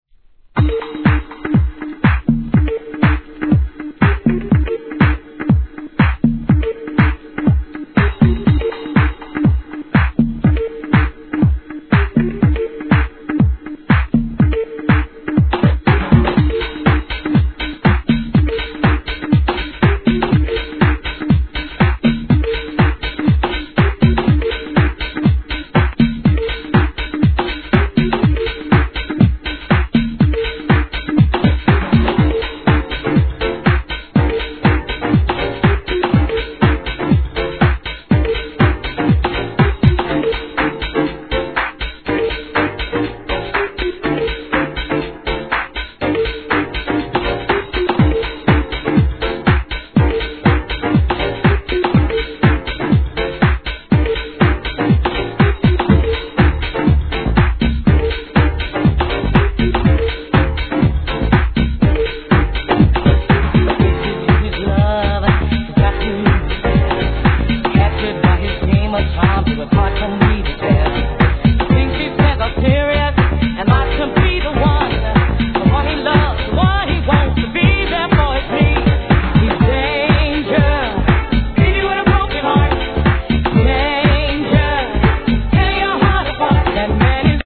王道のヴォーカルHOUSE!!